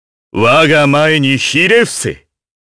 Kain-Vox_Victory_jp.wav